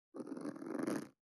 419,ジッパー,チャックの音,洋服関係音,
ジッパー